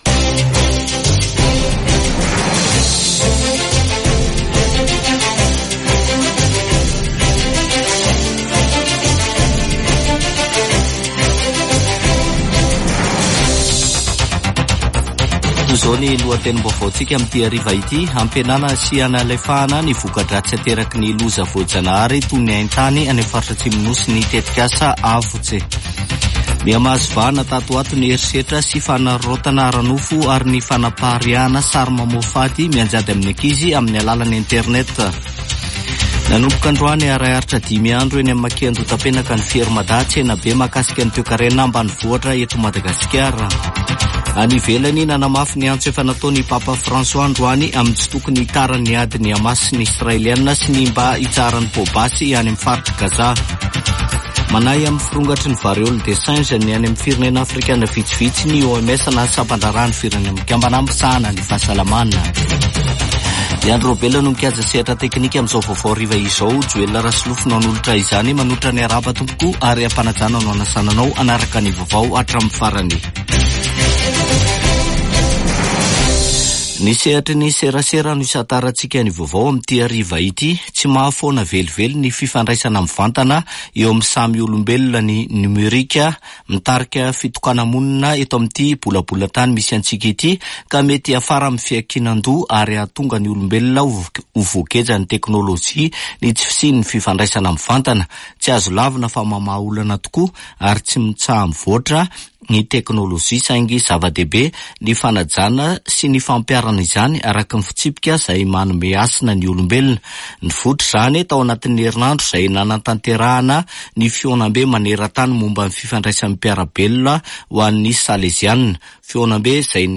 [Vaovao hariva] Alarobia 7 aogositra 2024